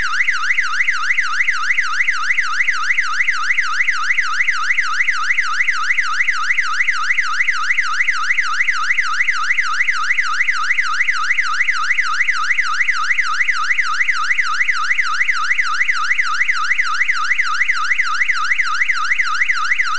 サイレン音試聴　1トーン
1/8音色サイレン
追加用として使用可能な１音色または８音色サイレン
1tone.wav